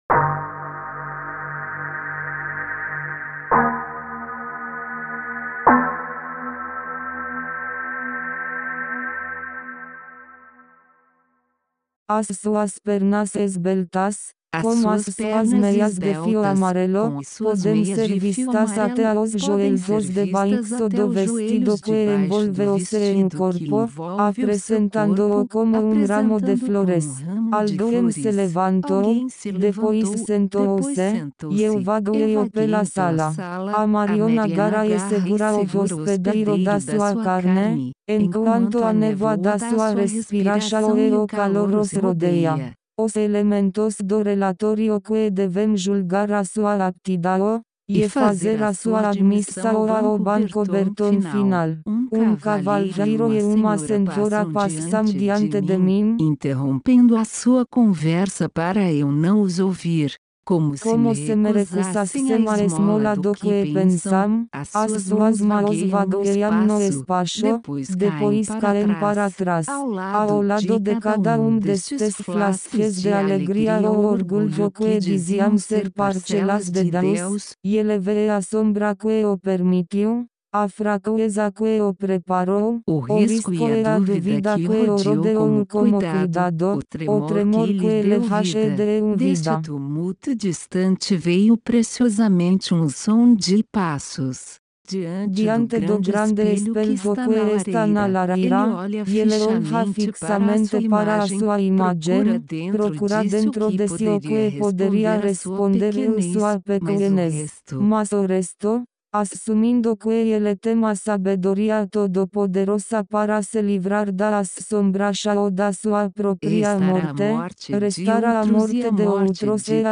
Ces récits peuvent être traduits en autant de langues que proposent les divers traducteurs automatiques et lus par n’importe quelle voix disponible ; ce qui rend possible un infini de textes différents bien que se situant dans le même mulivers.
lecture_6.mp3